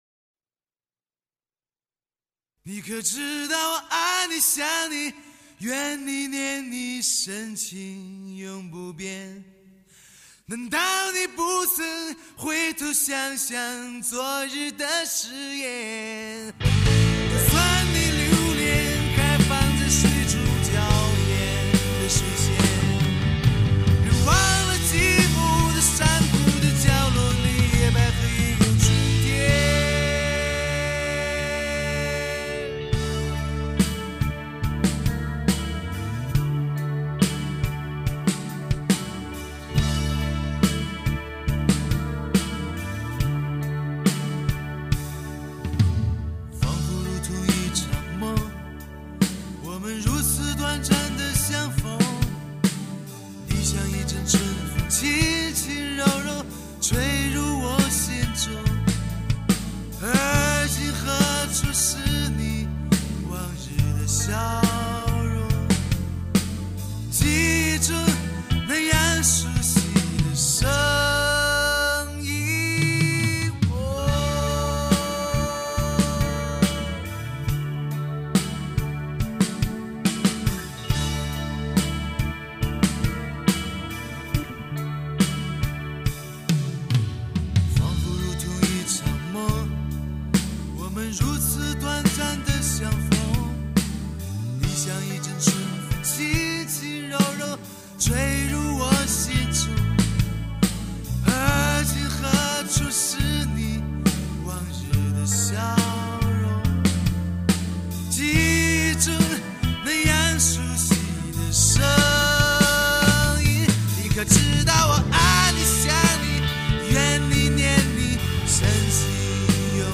摇滚群星深情演绎